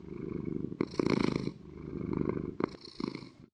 purr3.ogg